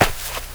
DIRT 4.WAV